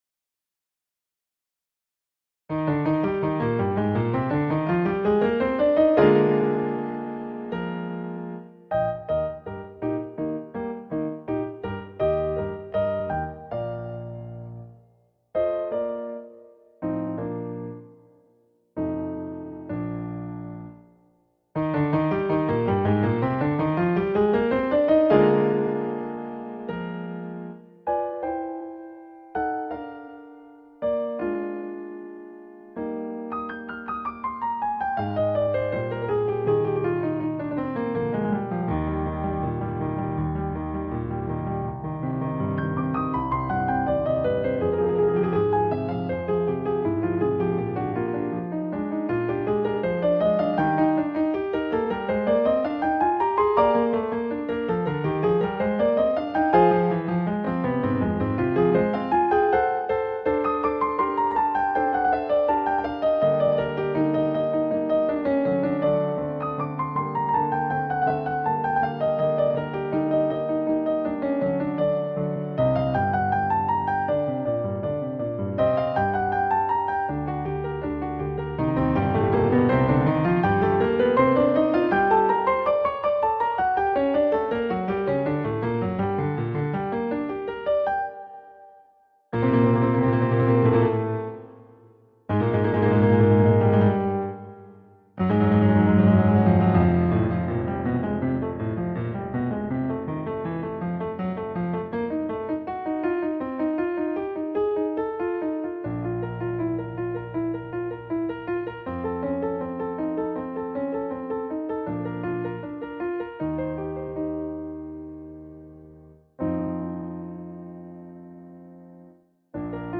Weber Allegro Con Fuoco Slower